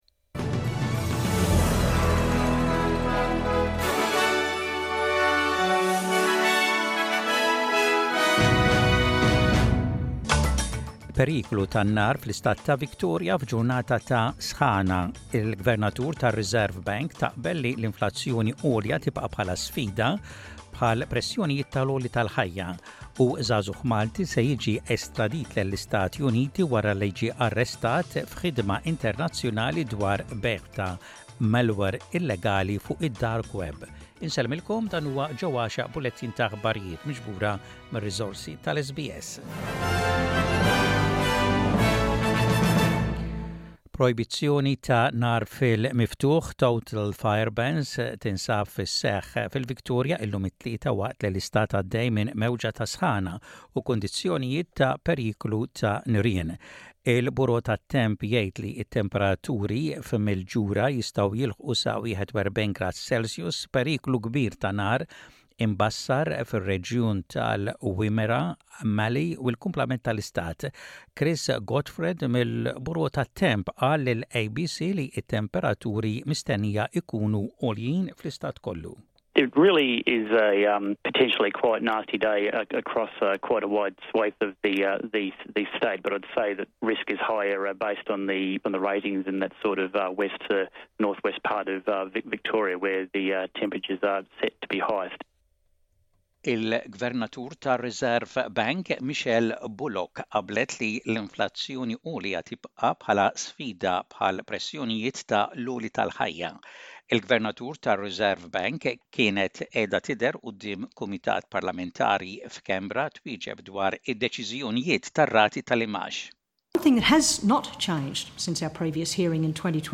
SBS Radio | Maltese News: 13.02.2024